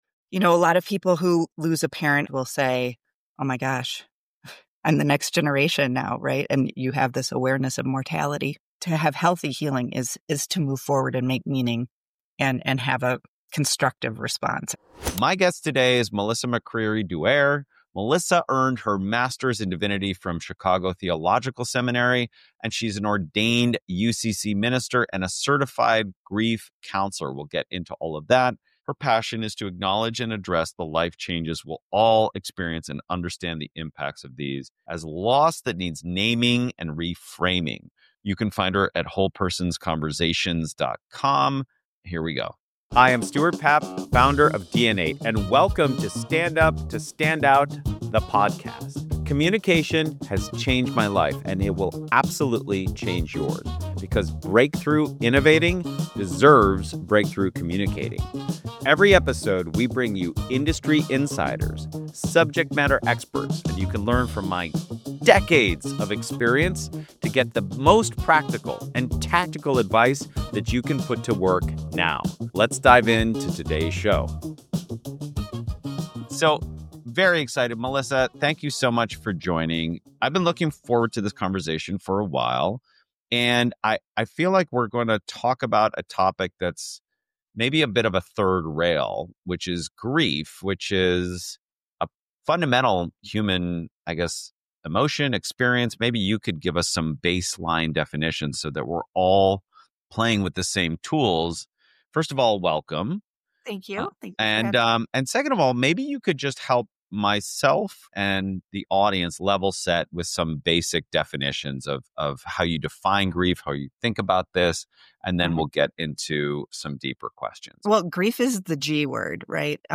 Join us for a profoundly insightful and essential conversation